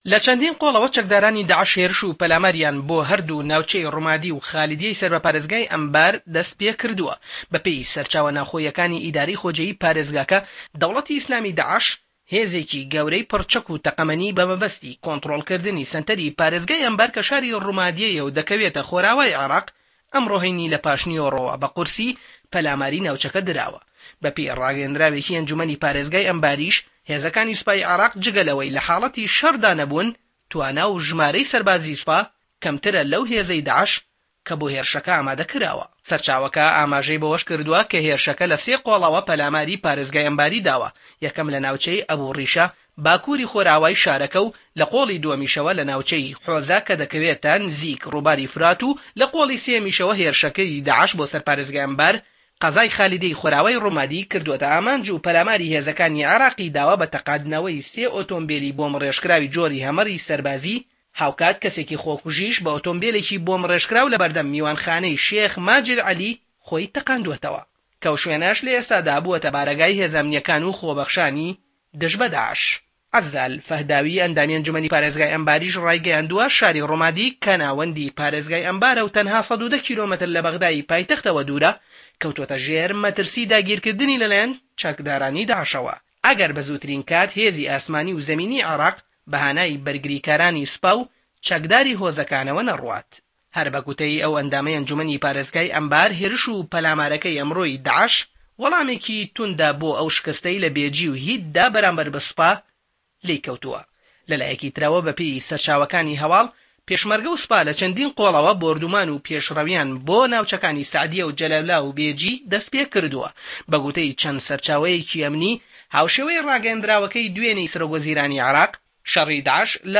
ڕاپـۆرتێـک له‌ باره‌ی هێرشی داعش بۆ سه‌ر شاری ڕومادی ناوه‌ندی پارێزگای ئه‌نبار